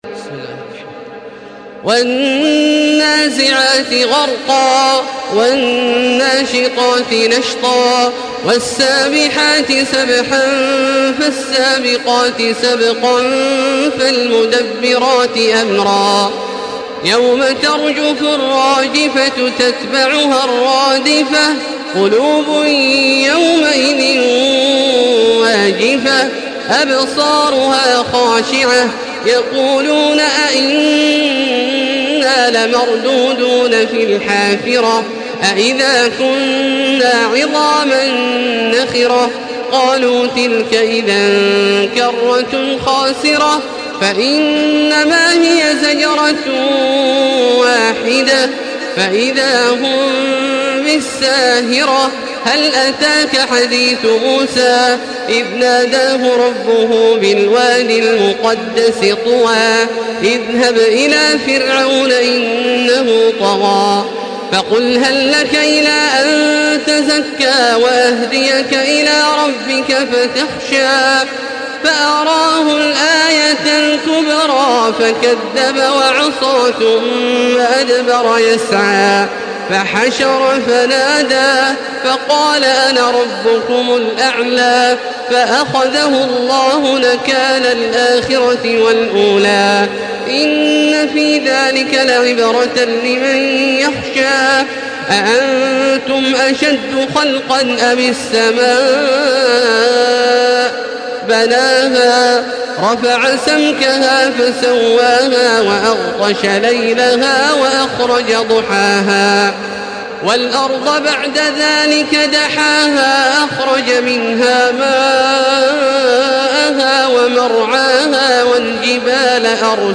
Surah An-Naziat MP3 in the Voice of Makkah Taraweeh 1435 in Hafs Narration
Listen and download the full recitation in MP3 format via direct and fast links in multiple qualities to your mobile phone.
Murattal